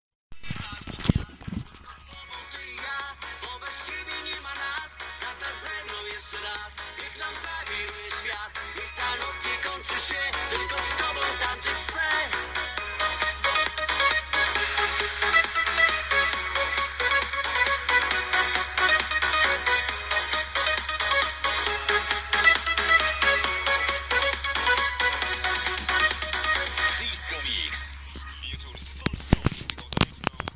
Nuta z radia disco polo